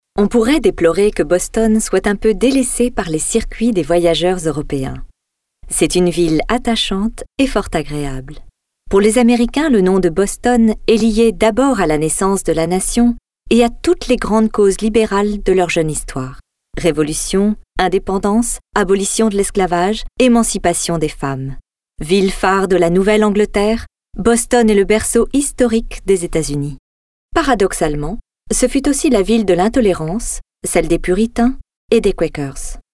Démo Voix off
Démo voix off pub